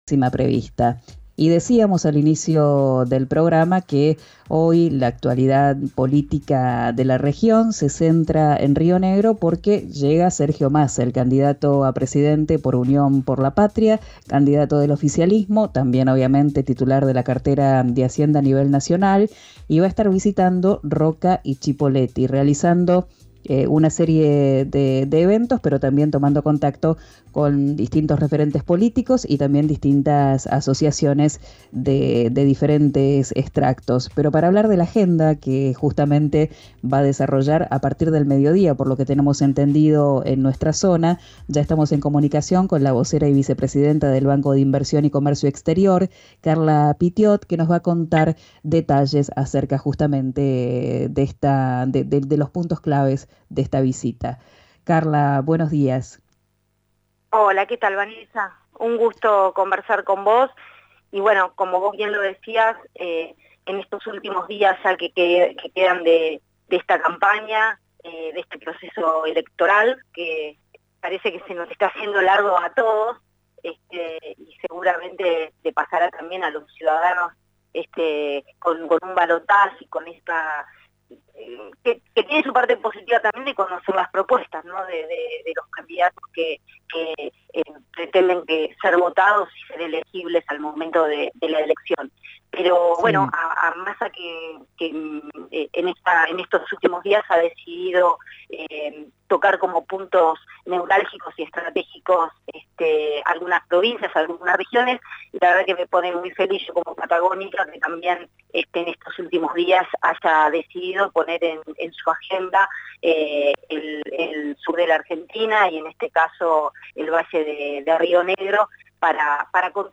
Primero estuvo en Roca y, luego encabezó un acto en Cipolletti.
En su discurso, planteó diferentes propuestas a nivel provincial y también algunas específicas para los vecinos de Cipolletti.